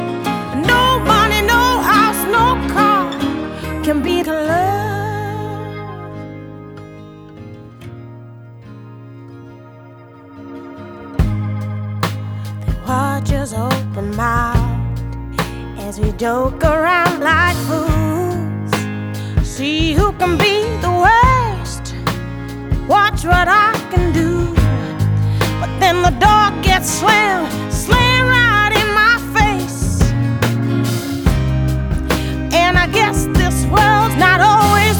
Жанр: Поп музыка / Рок / R&B / Танцевальные / Соул